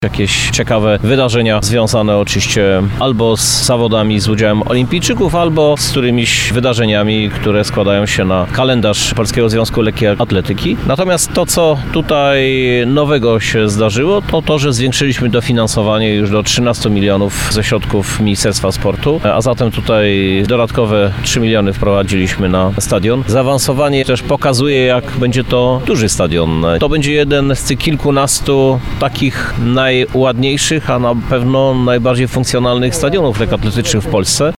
– Już dziś rozmawiamy z Polskim Związkiem Lekkiej Atletyki, by na jesieni odbyła się inauguracja obiektu – informuje prezydent Lublina, Krzysztof Żuk.